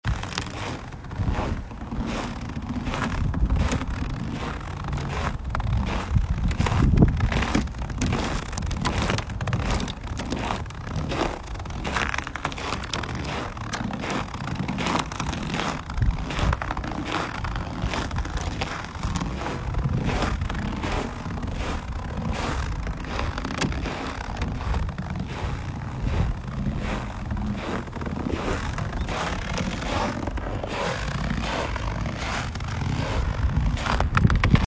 눈 밟고 뽀드득뽀드득!
눈 밟는 소리 너무 좋다!